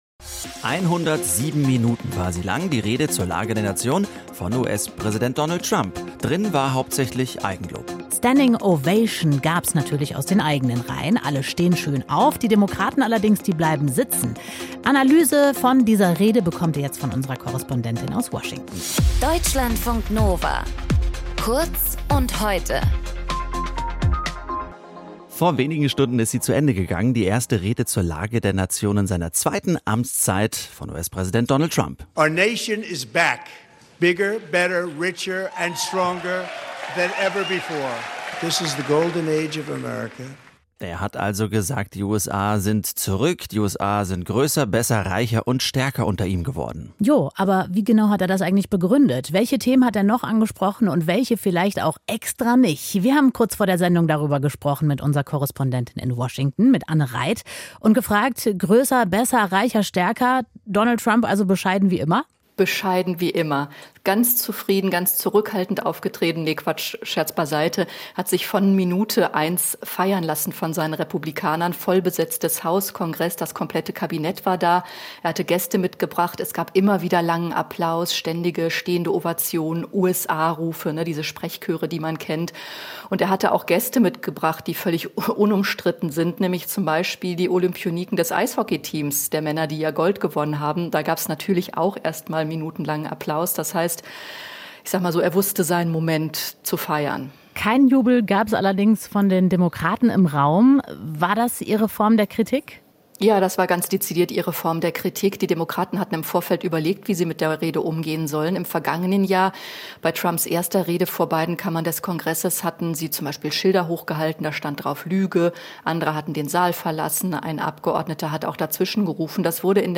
Moderation
Gesprächspartnerin